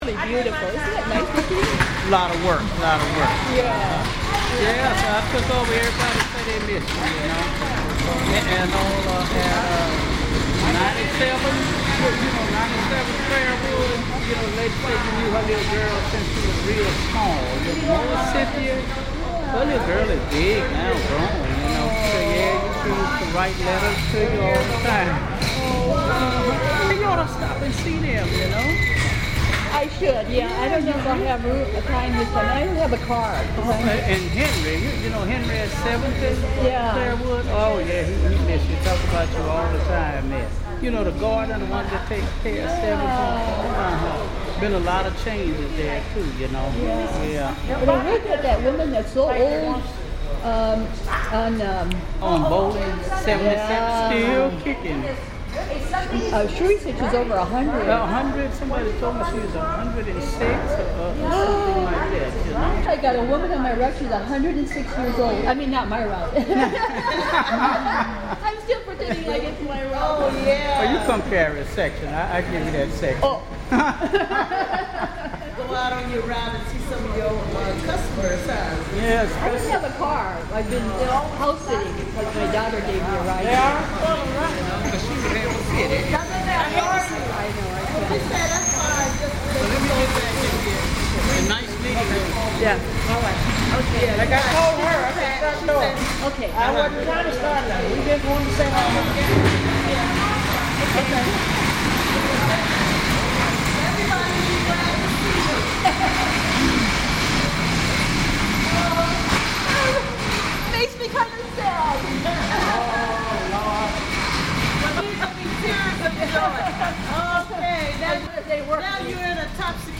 The mail carriers are just leaving to their routes to deliver the mail with their carts.